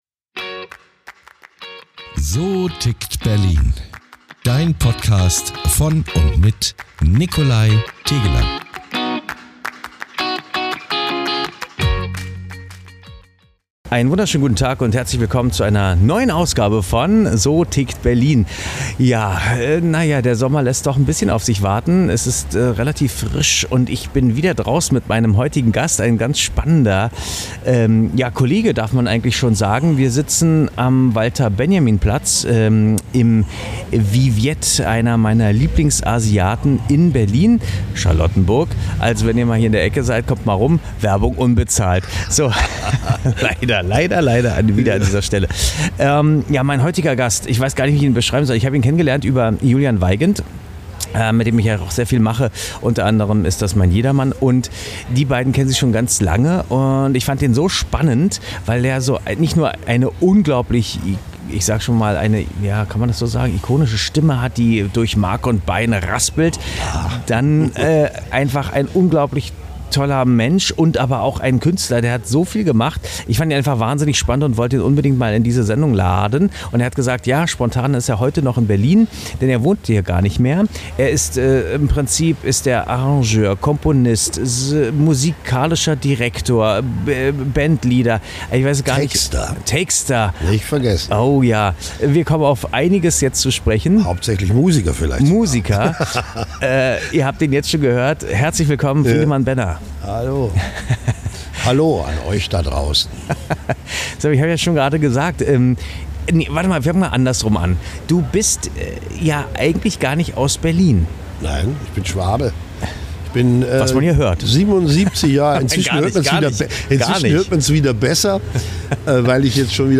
Intro- / Outro-Sprecher